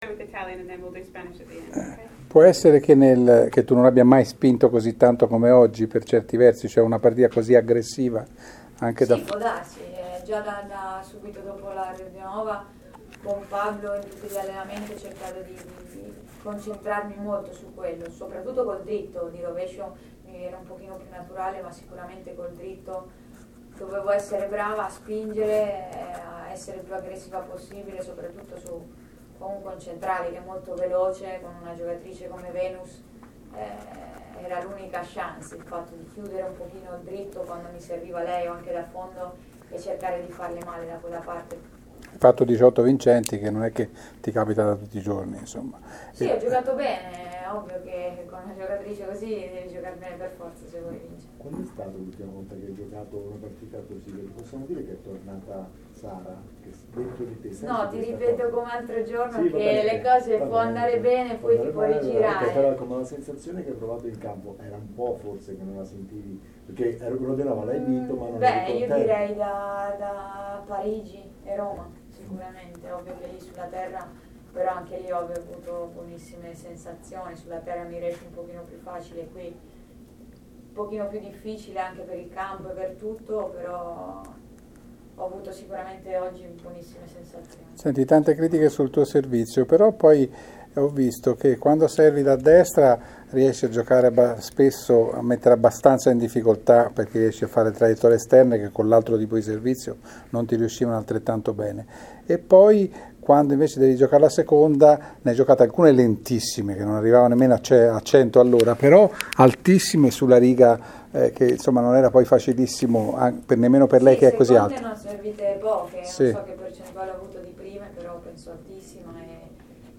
Sara Errani dopo il successo al secondo turno:
Nel secondo, hanno ceduto un po’ le gambe” (intervista in inglese)